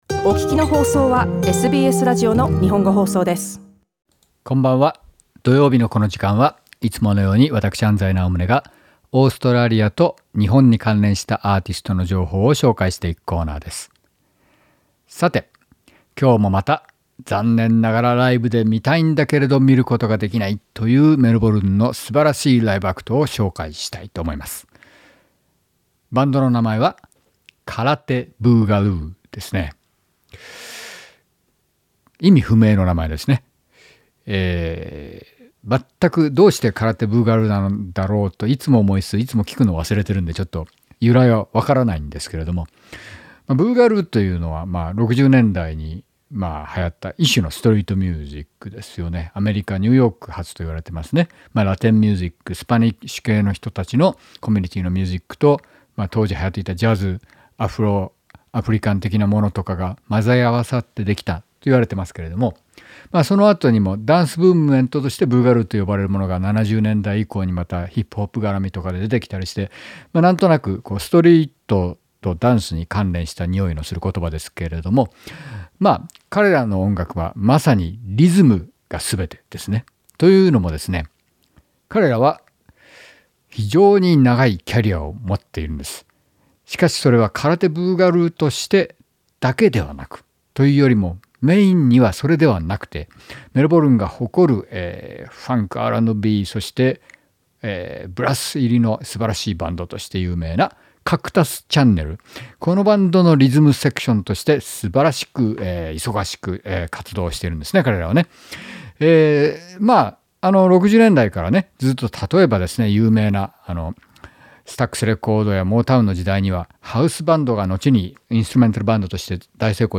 今回は自分たちのみ、すなわちリズムセクションのみのインストルメント・バンドとしてアルバムを出しました。